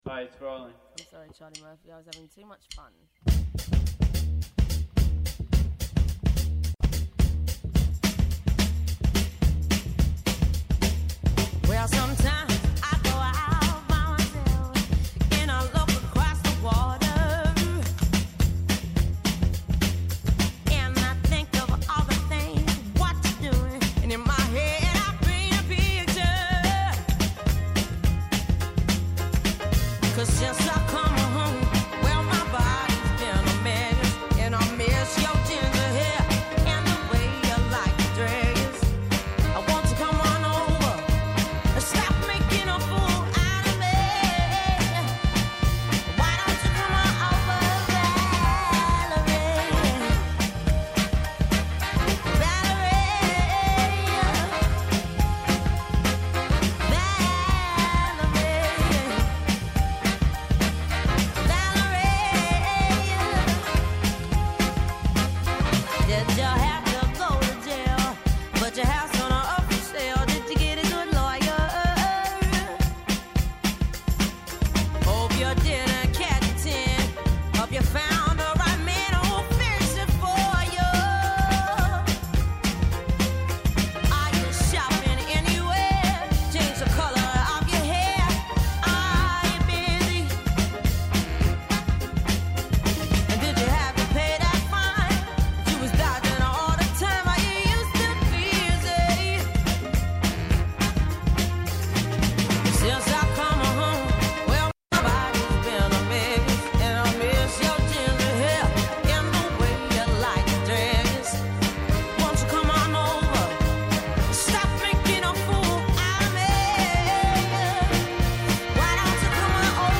Καλεσμένος σήμερα ο Δημήτρης Παρασκευής, Αντιπρόεδρος του ΕΟΔΥ και Καθηγητής Επιδημιολογίας και Προληπτικής Ιατρικής στο ΕΚΠΑ για μια συζήτηση τρία χρόνια μετά την πανδημία του κορονοϊού με αφορμή την άρση μέτρων όπως η χρήση μάσκας στα Μέσα Μαζικής Μεταφοράς και η υποχρέωση επίδειξης αρνητικού τεστ εκτός από μονάδες υγείας και παροχής φροντίδας ηλικιωμένων.